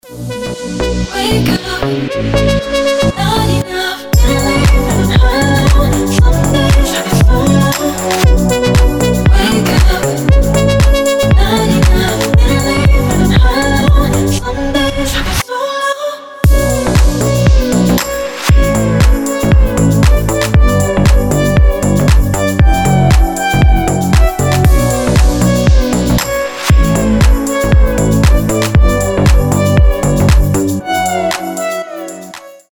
deep house
мелодичные
чувственные
теплые
Чувственная и тёплая музыка